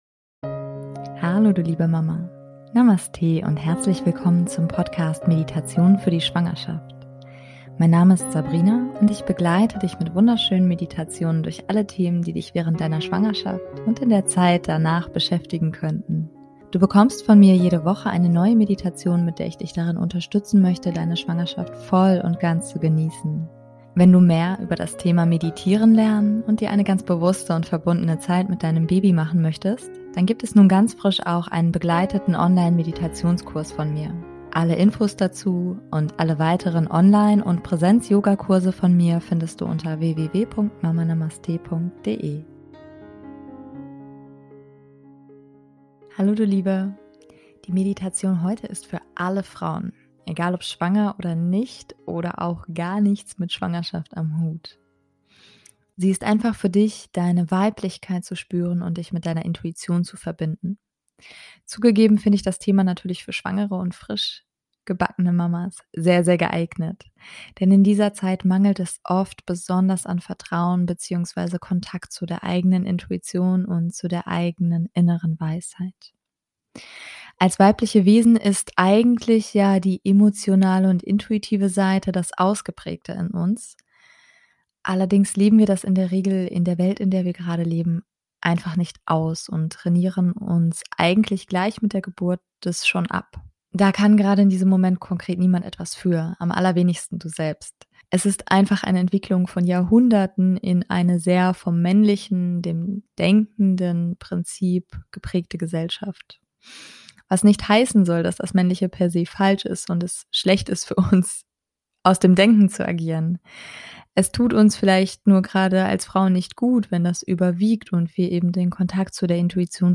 #111 - Treffen mit deiner inneren weisen Frau - Meditation [Für Alle] ~ Meditationen für die Schwangerschaft und Geburt - mama.namaste Podcast